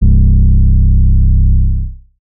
TS - 808 (6).wav